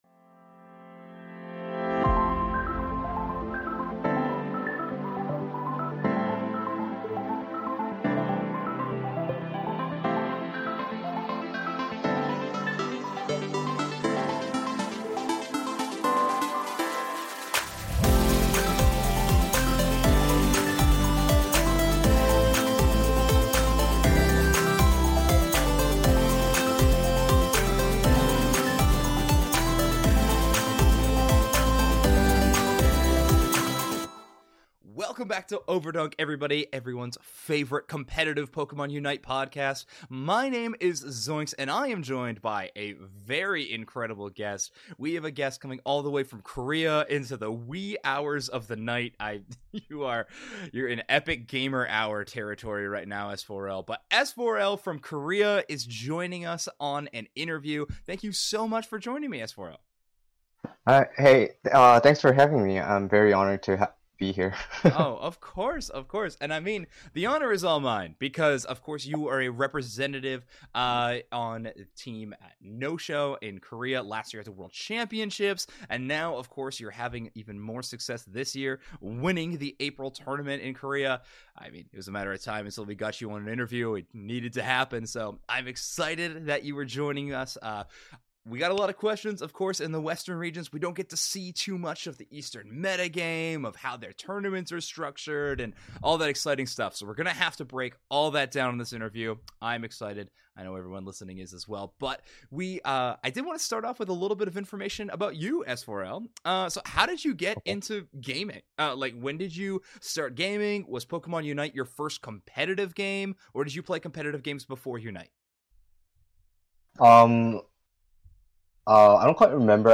Overdunk Ep. 67 Interview